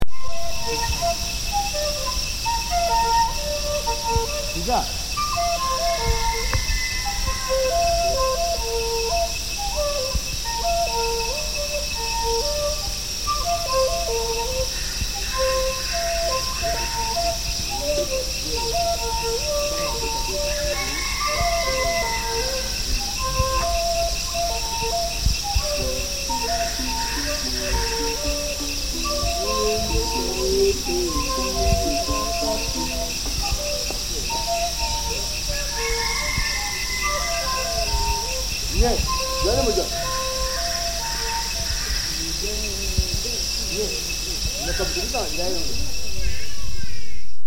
Instruments in the forest at dawn
Instruments in the forest at dawn (flute and harp).